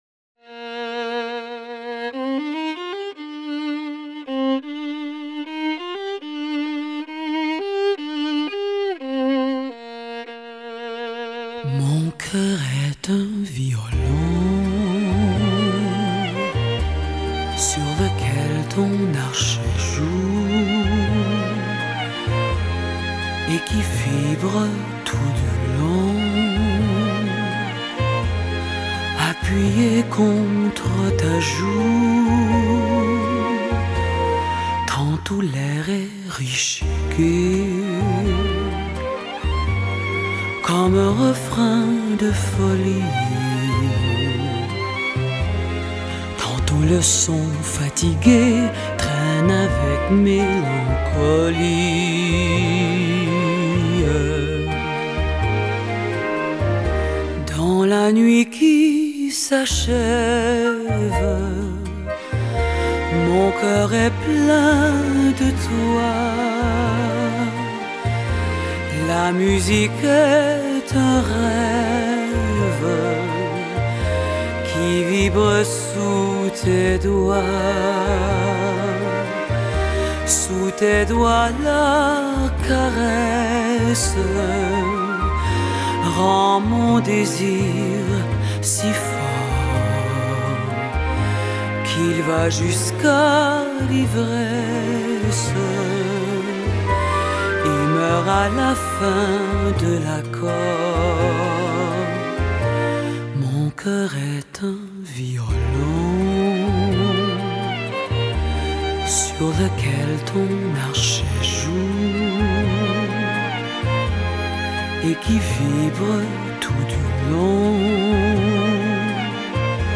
法国香颂经典 独一无二最迷人的提琴演唱版